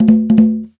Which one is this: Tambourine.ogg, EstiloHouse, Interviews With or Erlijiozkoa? Tambourine.ogg